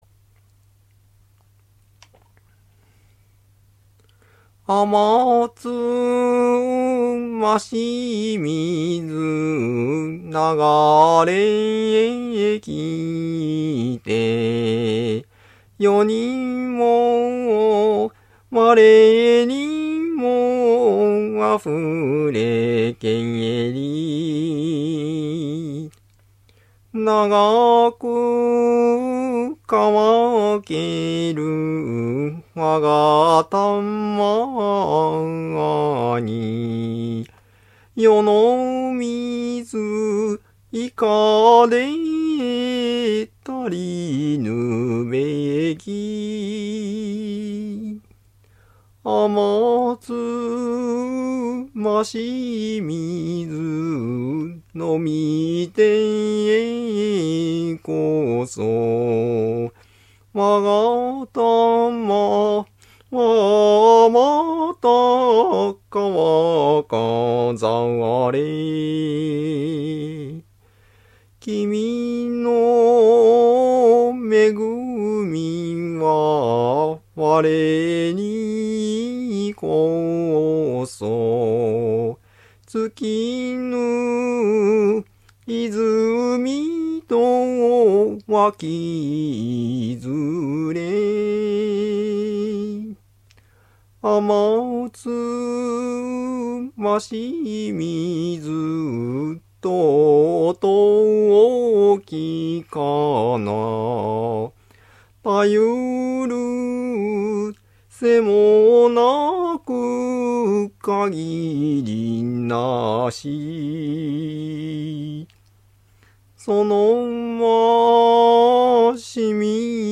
唄楽庵　讃美歌「あまつましみづ」都々逸風
ここでは敢えて適当にチントンシャンと都々逸風の節を付けてみました。